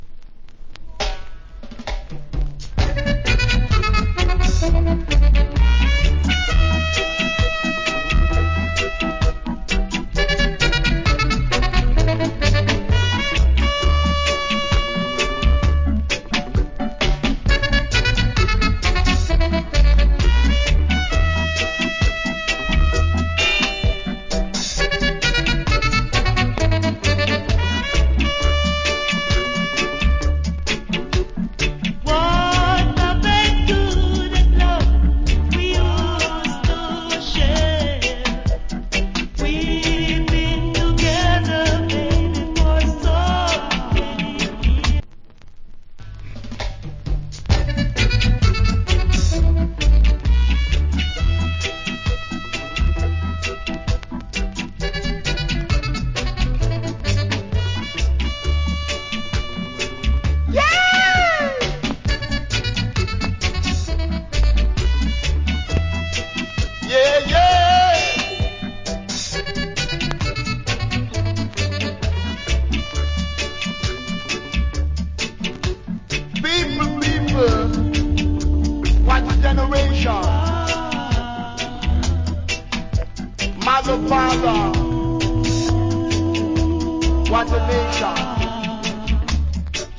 Cool Female Reggae Vocal.